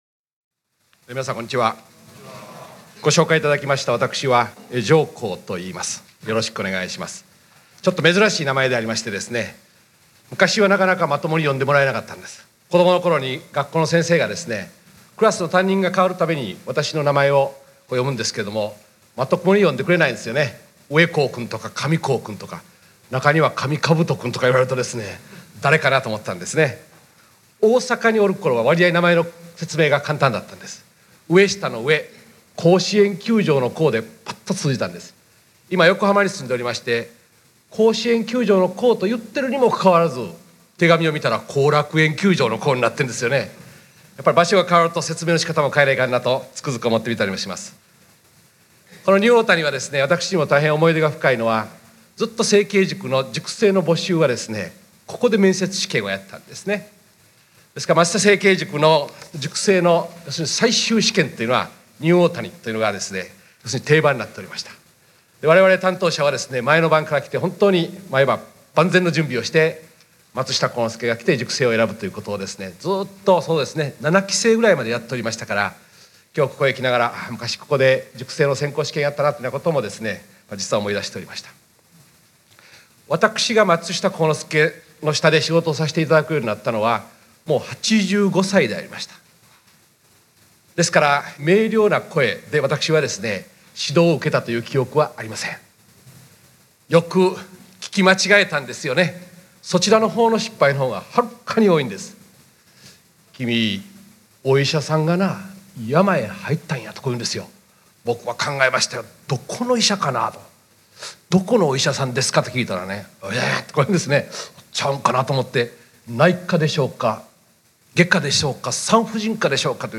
※この音声は、平成10年に開催された「松下幸之助に学ぶ経営者・幹部の経営心得」での講演を収録したもので、「実践・経営哲学塾」（カセットテープ）として発売した内容と同じです。